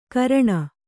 ♪ karaṇa